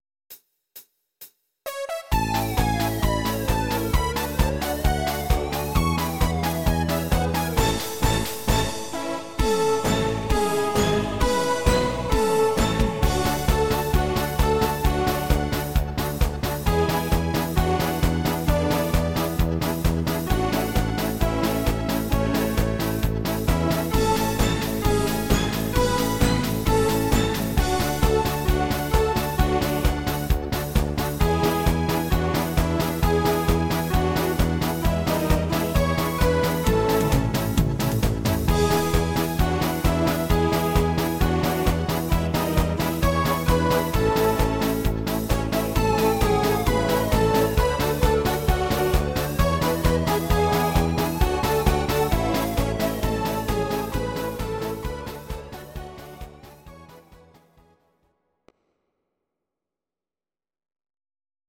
Audio Recordings based on Midi-files
German, Traditional/Folk